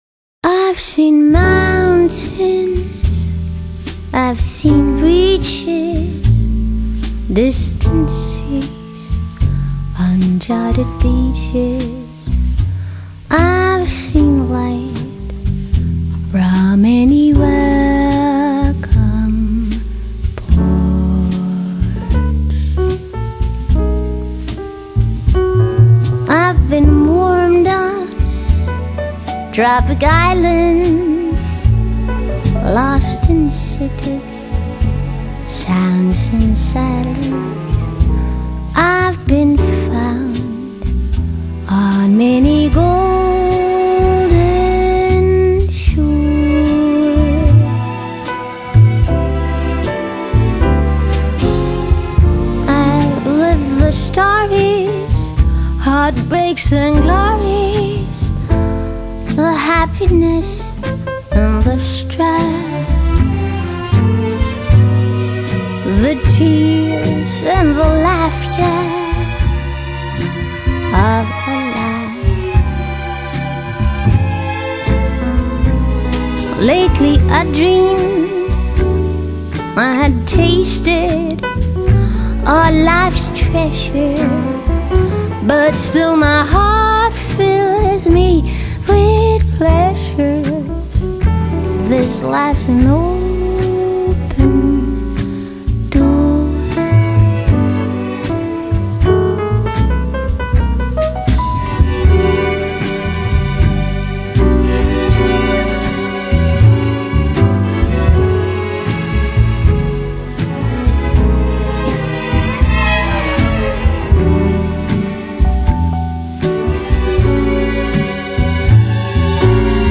爵士/蓝调
童稚而沙哑声音习惯在夜晚覆盖整个欧陆。